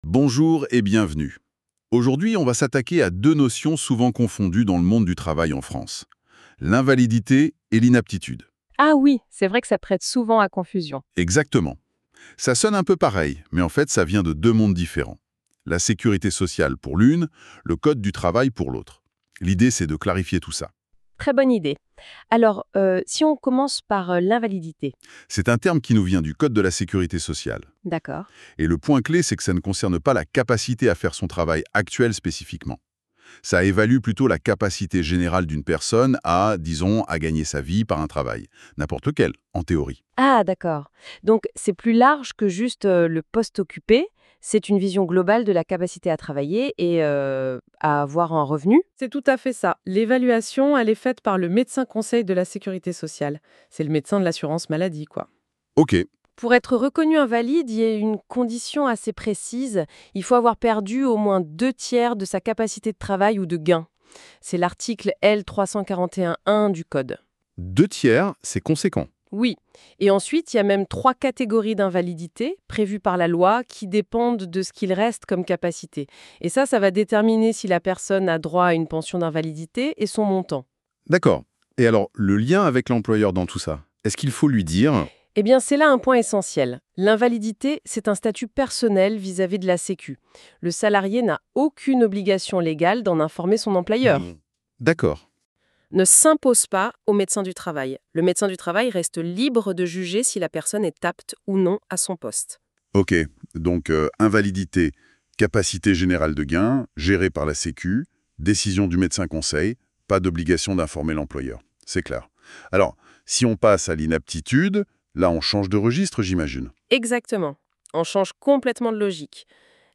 Podcast invalidité vs inaptitude au travail (GenIA) :